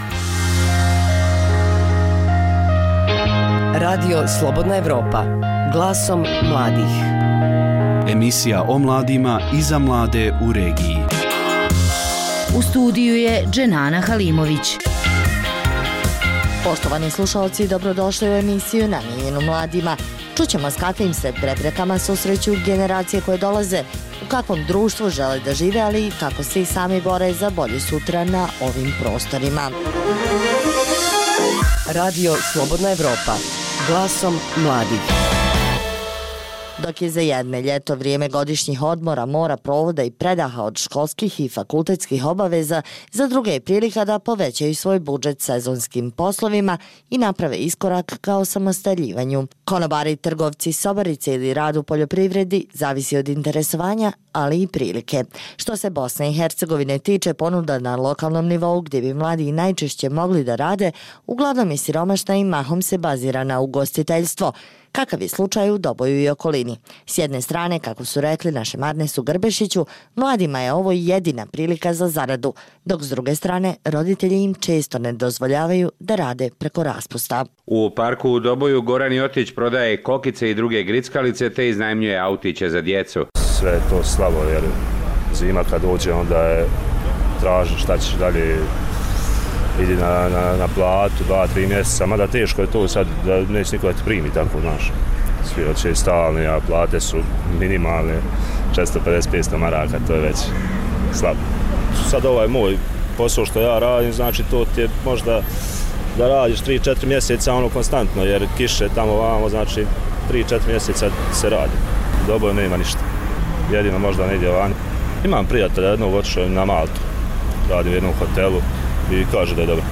O tome govore mladi iz balkanskog regiona. No, i volonterski rad je od izuzetne važnosti za zajednicu.